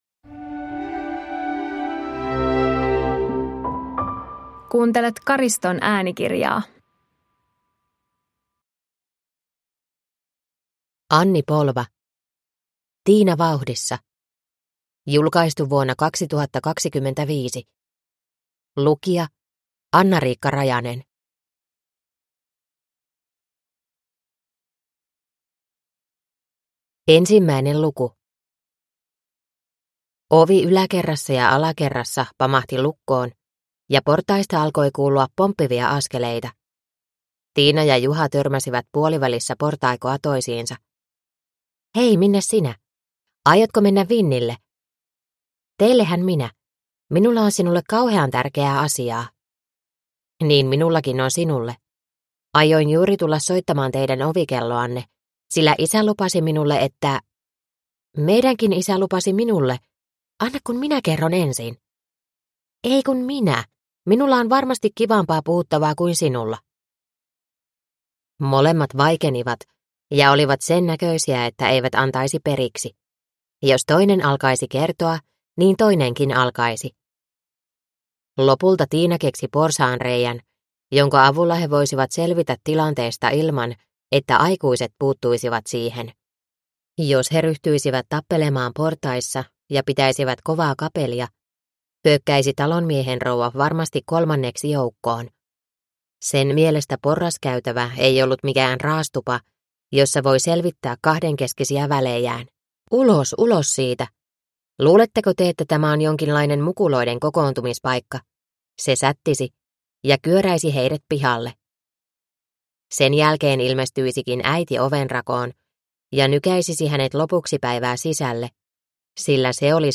Tiina vauhdissa (ljudbok) av Anni Polva